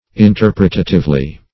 Interpretatively \In*ter"pre*ta*tive*ly\, adv.